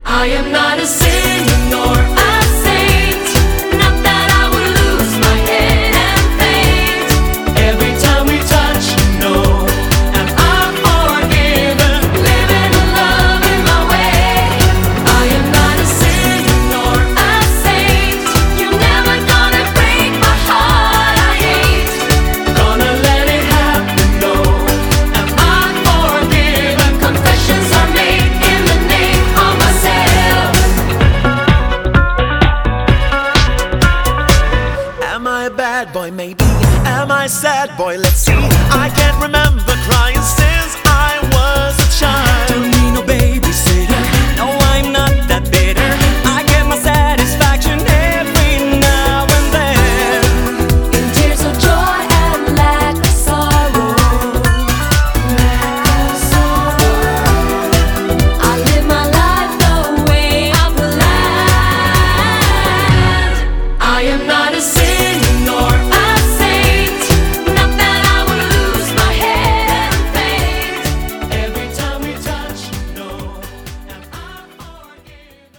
• Качество: 256, Stereo
поп
евродэнс